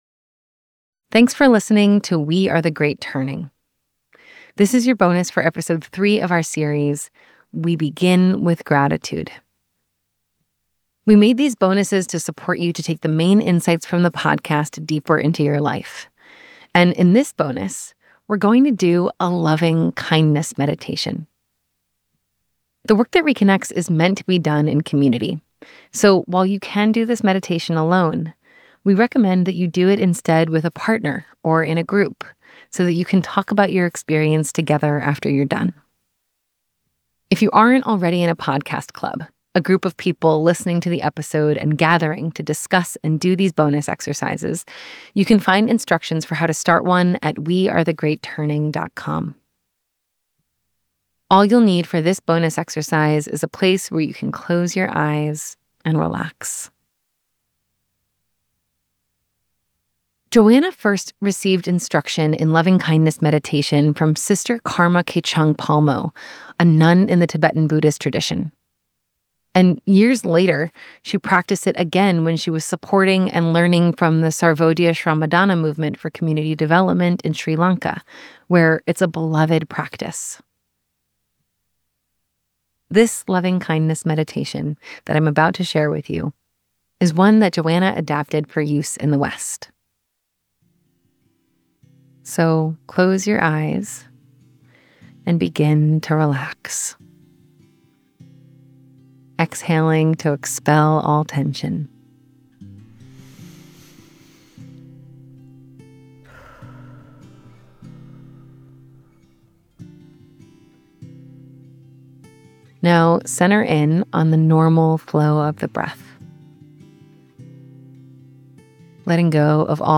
A meditation to bless everyone including NHI